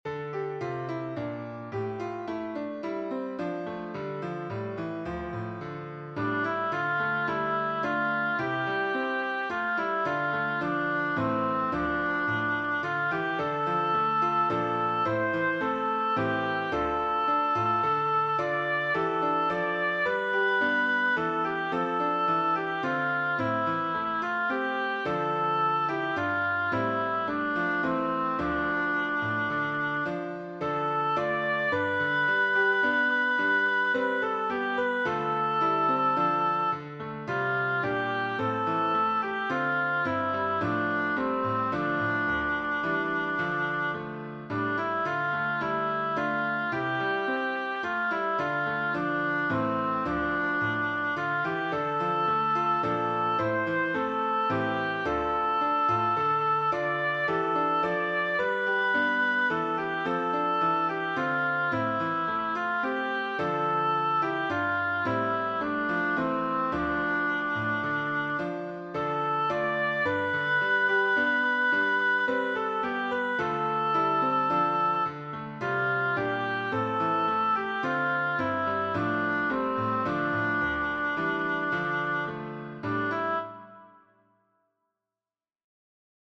Hymn composed by